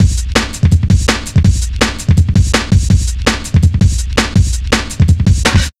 Index of /90_sSampleCDs/Zero-G - Total Drum Bass/Drumloops - 3/track 51 (165bpm)